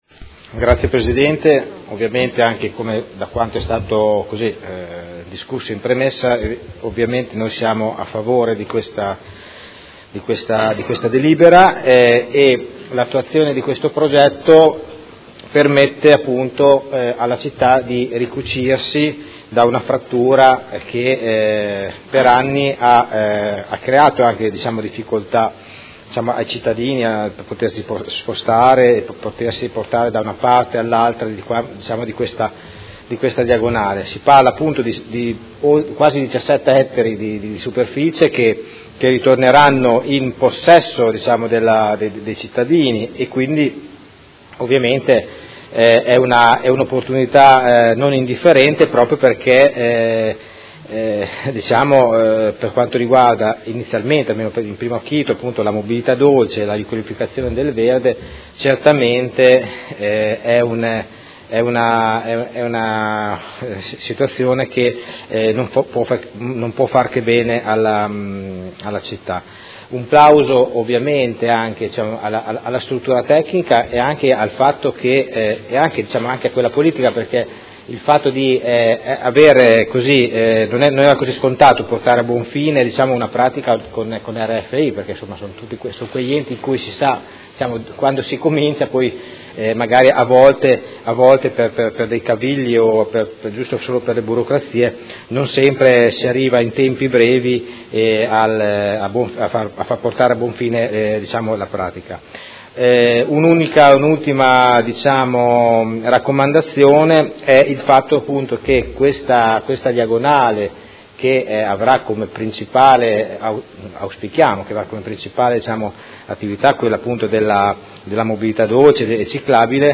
Seduta del 28/03/2019. Dichiarazioni di voto su proposta di deliberazione: Costituzione di un diritto di superficie a favore del Comune di Modena delle aree di sedime dell'ex Ferrovia dismessa di proprietà RFI Spa – Linea Mi-BO nel tratto tra Cittanova e San Cataldo - Approvazione